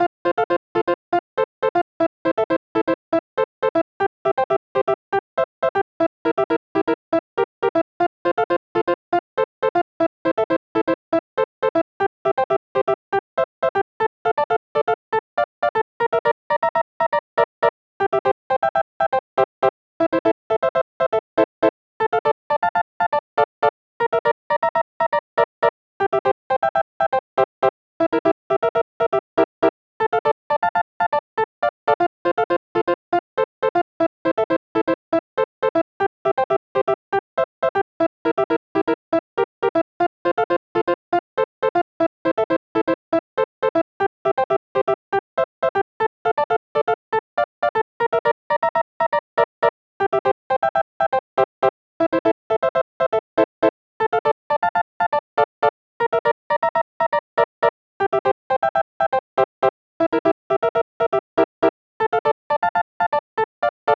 リリースカットピアノのおしゃれなコードが決め手！
ループ：◎
BPM：120 キー：F ジャンル：あかるい、おしゃれ 楽器：リリースカットピアノ